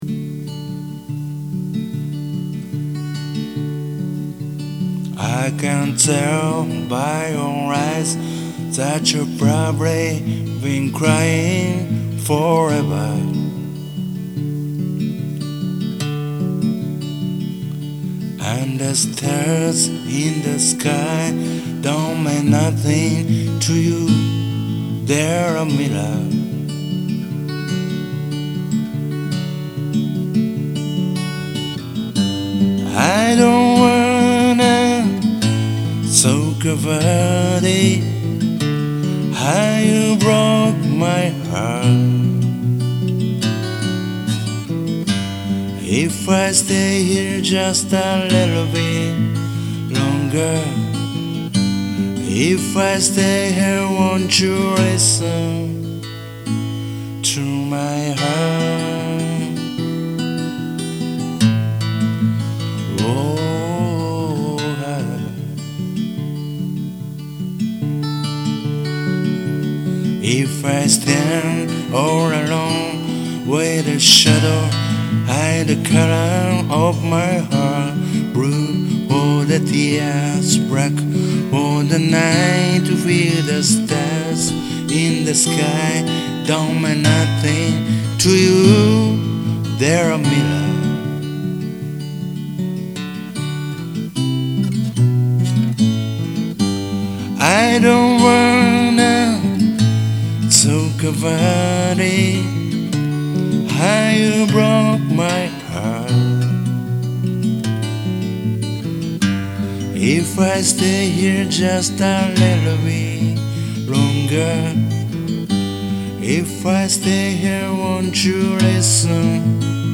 オリジナルとカヴァー曲をギターと歌でプライベート録音をしています。
バンドでもやったことがあるんだけど、今回はシンプルにやってみました。